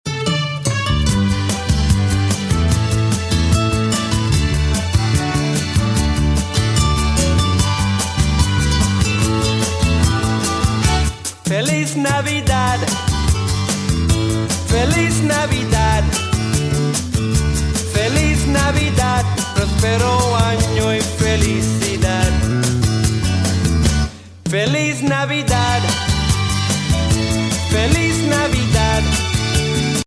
Composer: Male
Voicing: PVG Collection W